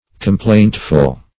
Complaintful \Com*plaint"ful\, a. Full of complaint.